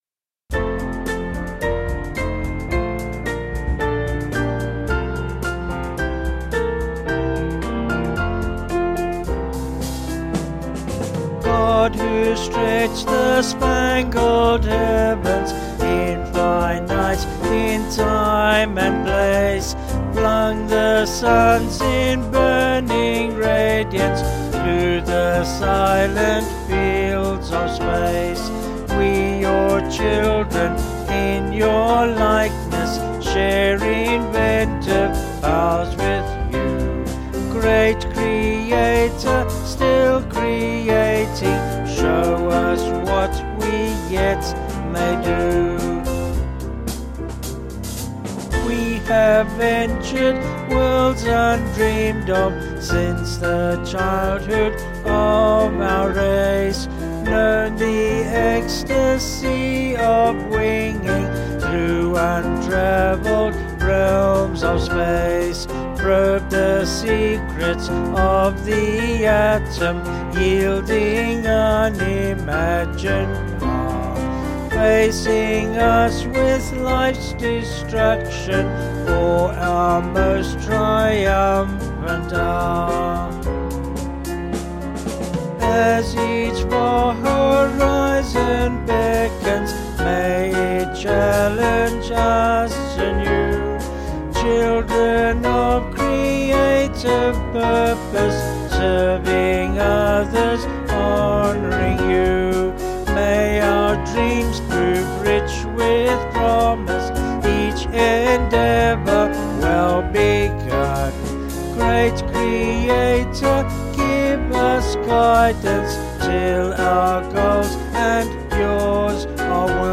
Vocals and Band   265.1kb Sung Lyrics